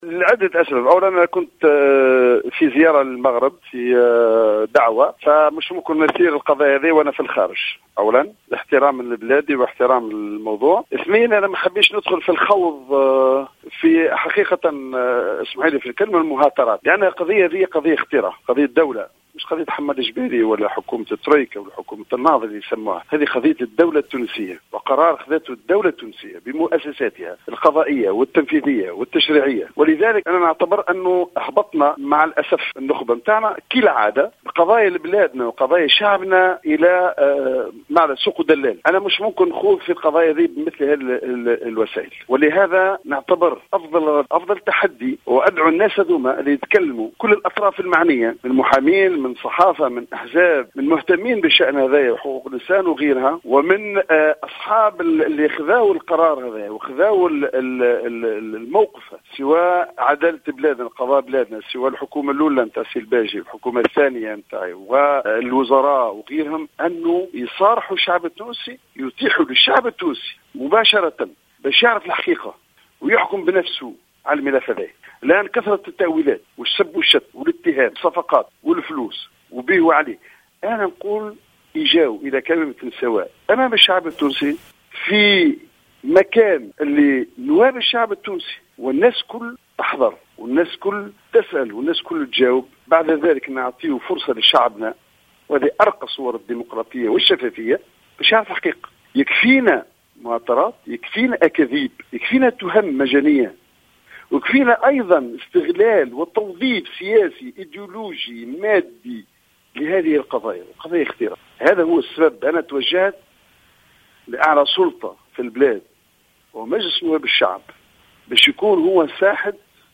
قال رئيس الحكومة الأسبق حمادي الجبالي في اتصال هاتفي مع الجوهرة أف أم اليوم الجمعة إنه تأخر على الرد على الجدل الحاصل في خصوص قضية تسليم الوزير الليبي الاسبق البغدادي المحمودي، بسبب غيابه عن البلاد حيث أدى مؤخرا زيارة إلى المملكة المغربية.